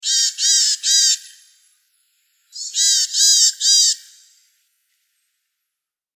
自然・動物 （50件）
ヤマガラ.mp3